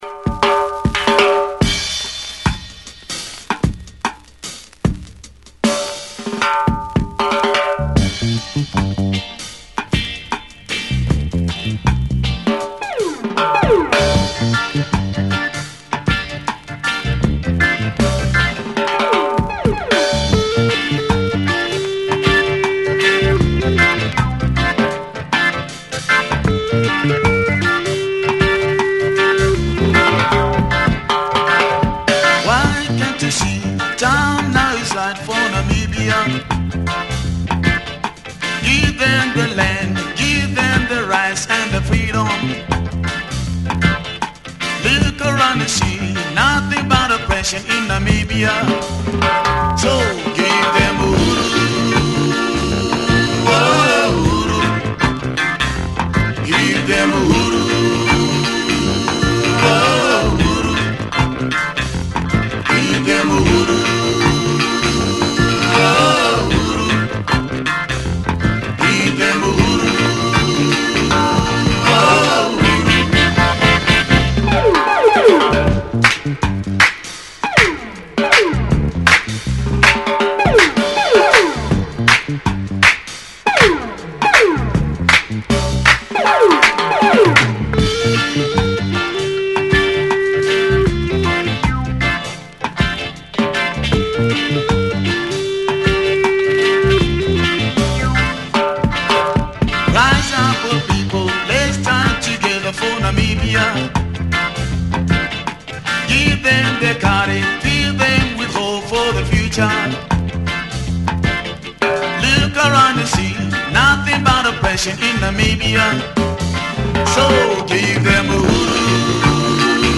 Reggae flavored coastal groover